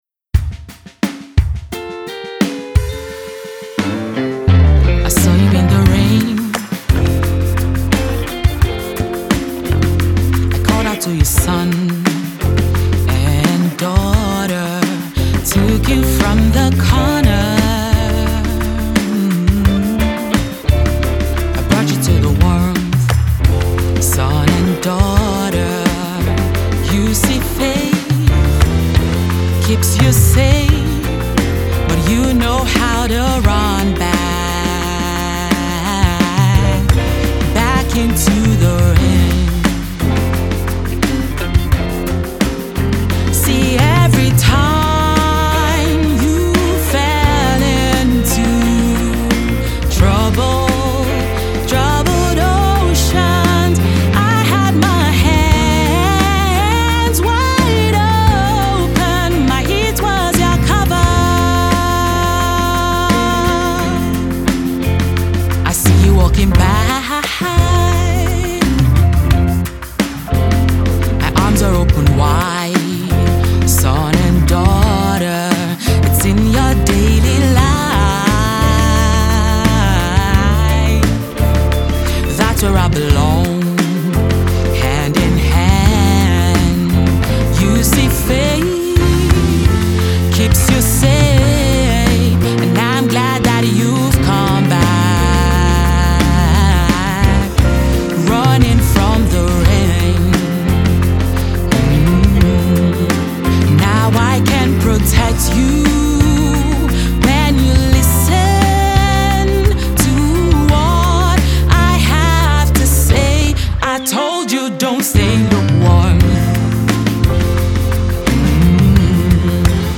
Latest Single
blues, soul, jazz, gospel, and contemporary music